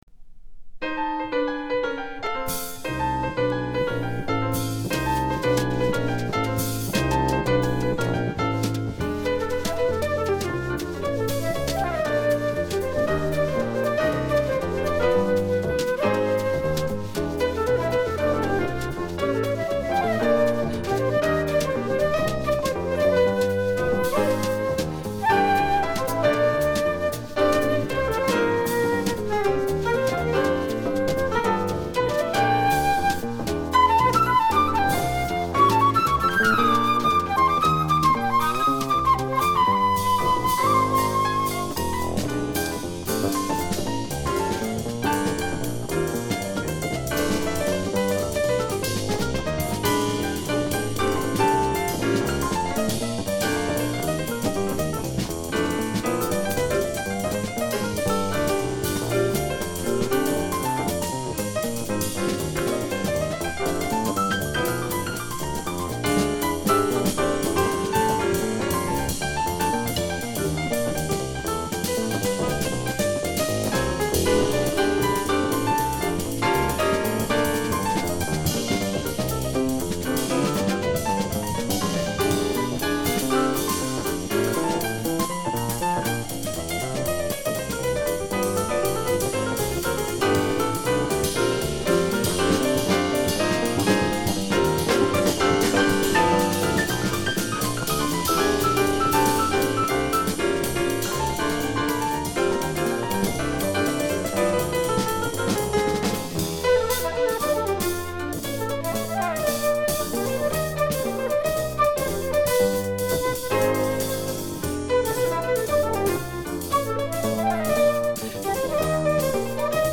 Flûte, piano, bass, drums / piano solo